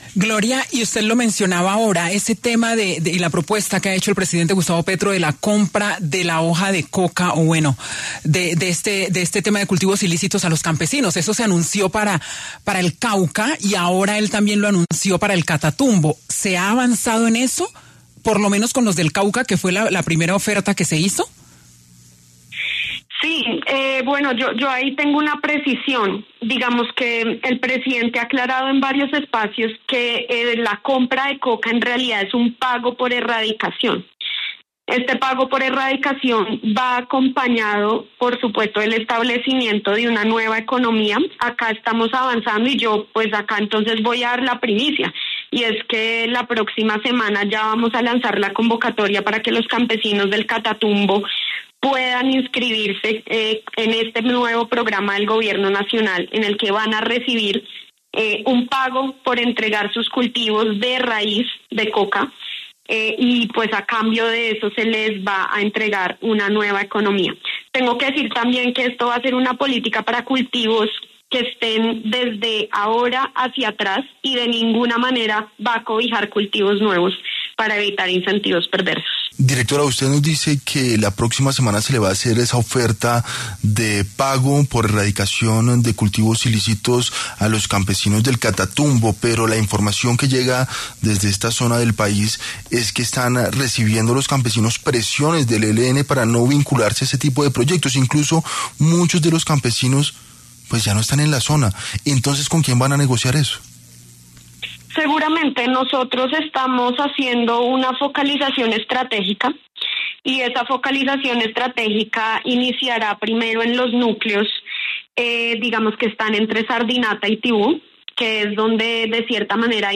“Es mucho más eficiente enfocarse en afectar el narcotráfico como una cadena de valor, como una empresa criminal, y, por tanto, en los actores que más se lucran de este negocio”, explicó Miranda en entrevista con W Radio.